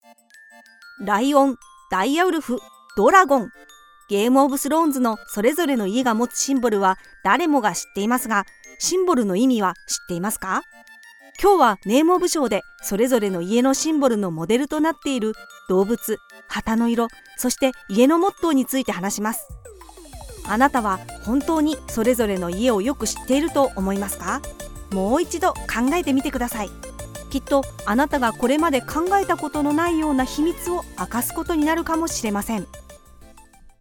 Commercial, Natural, Reliable, Warm, Corporate
personable, persuasive, versatile, warm and authentic